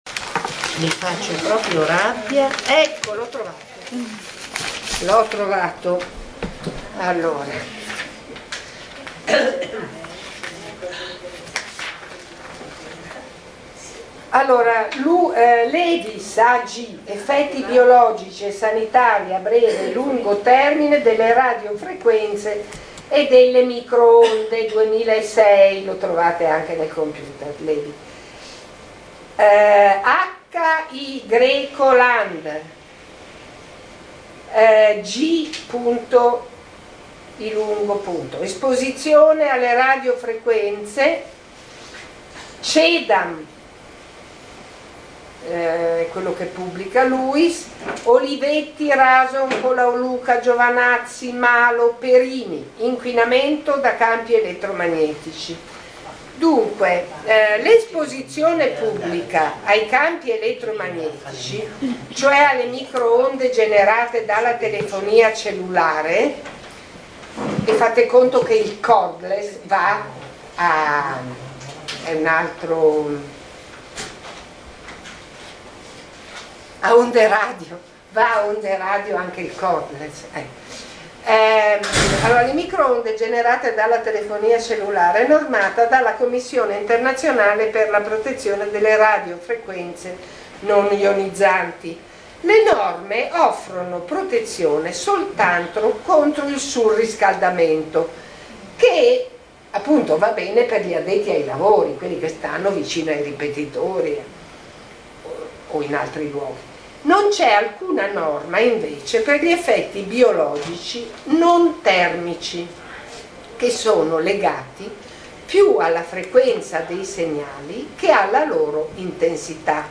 Registrazioni audio del Seminario Le donne per la salute: ambiente, consumi e stili di vita 17 marzo 2012